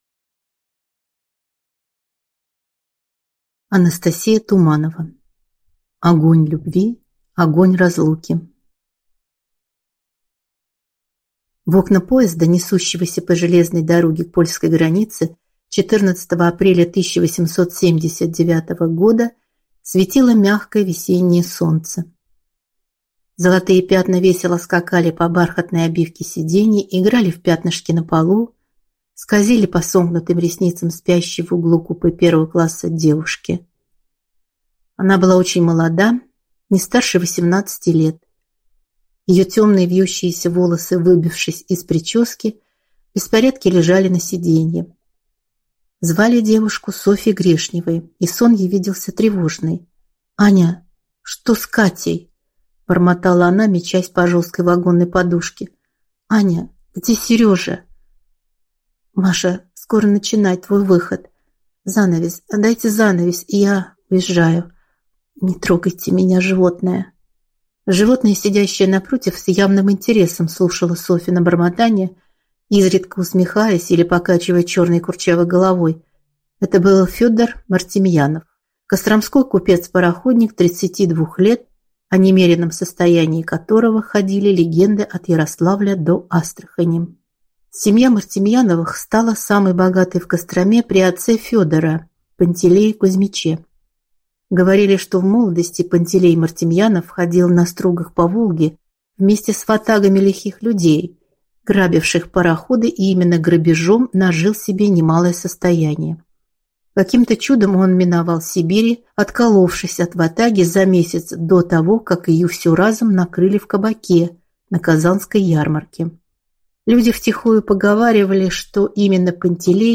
Аудиокнига Огонь любви, огонь разлуки | Библиотека аудиокниг